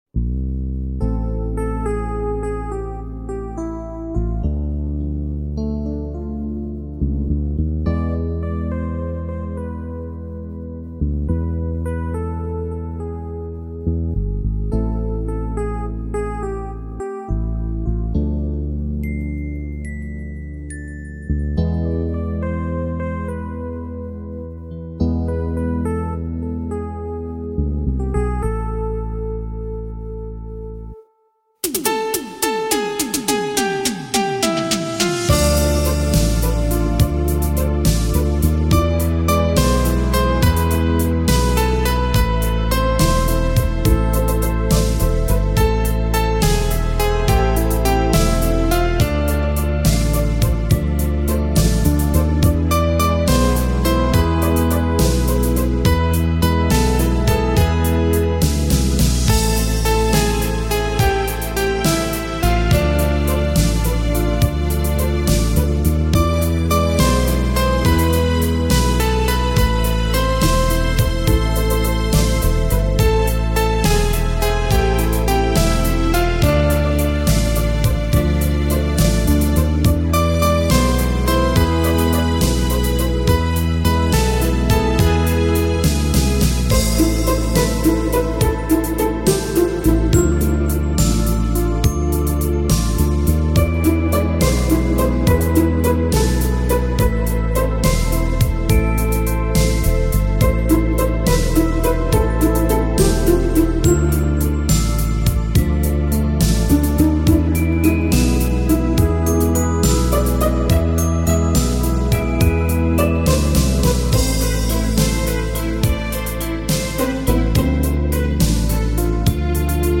Красивая мелодия.